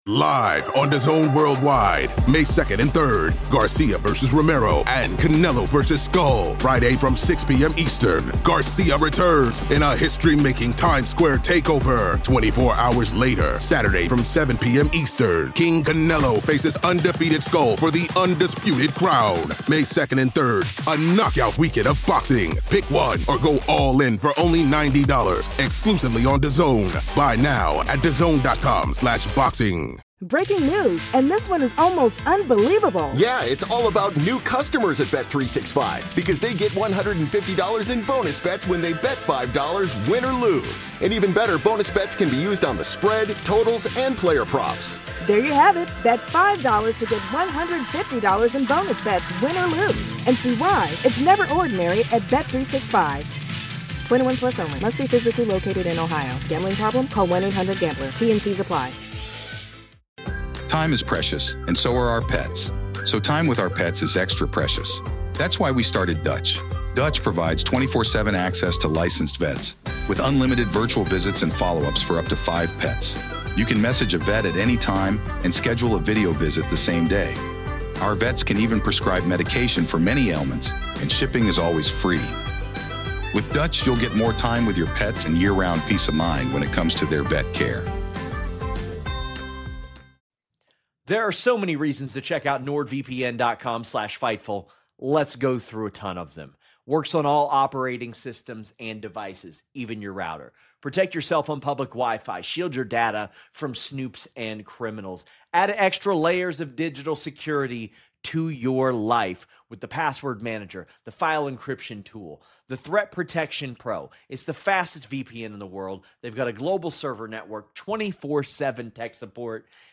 and Teams 1:42:15 Play Pause 18h ago 1:42:15 Play Pause Play later Play later Lists Like Liked 1:42:15 This is TLC Equation: Simply defined, this show explores certain universal truths behind the greatest teams, leaders, and cultures on earth, and how they have conquered the world’s most complex challenges. This episode features an insightful conversation